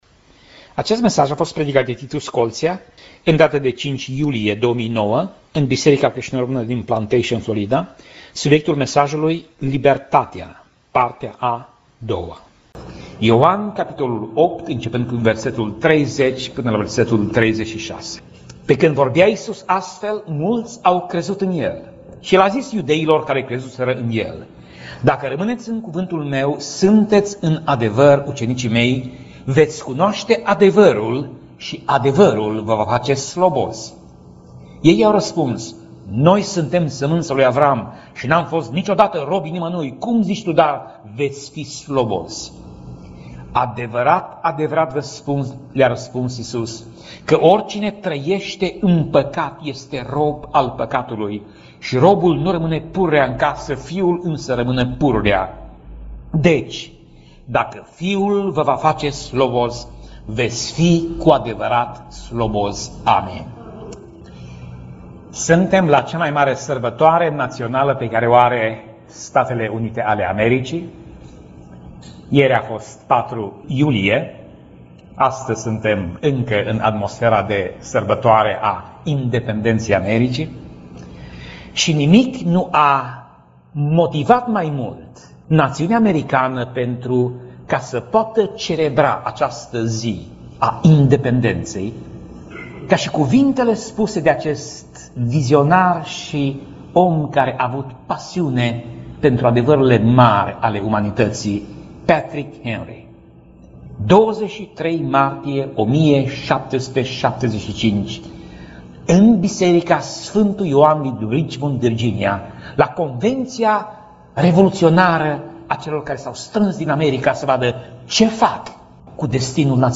Pasaj Biblie: Ioan 8:30 - Ioan 8:36 Tip Mesaj: Predica